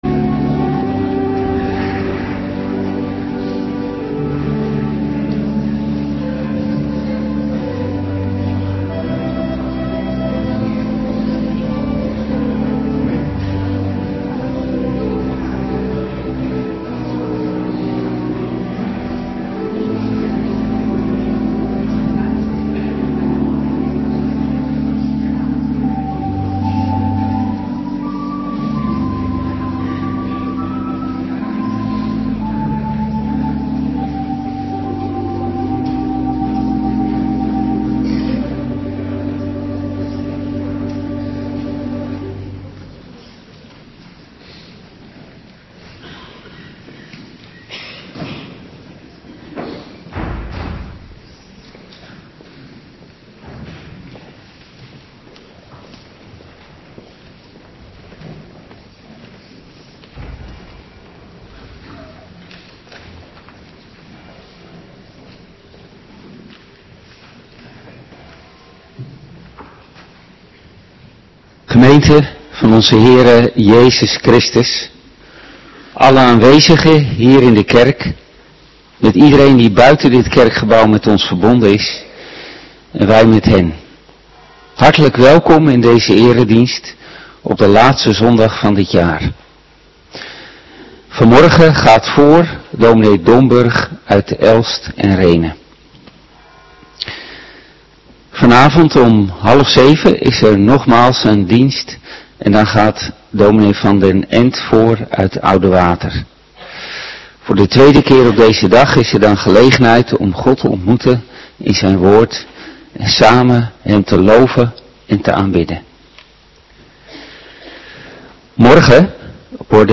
Morgendienst 28 december 2025